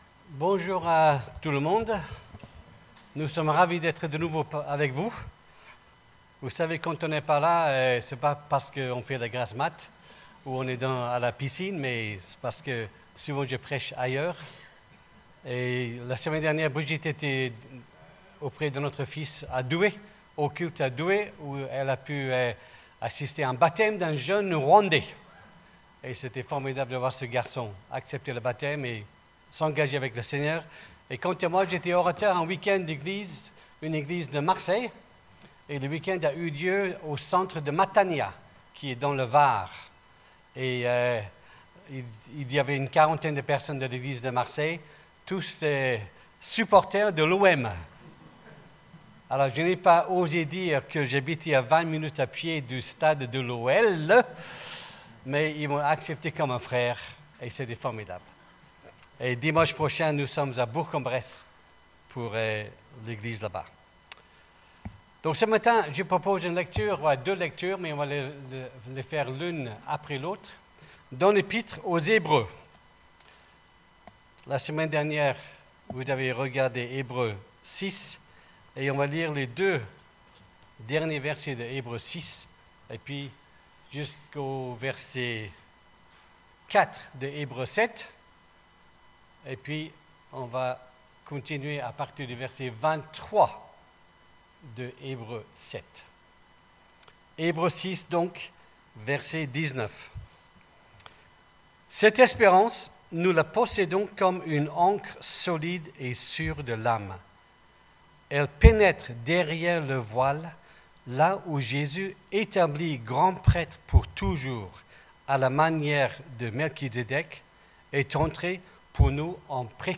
Prédications – Page 3 – Eglise Protestante Evangélique Lyon 8°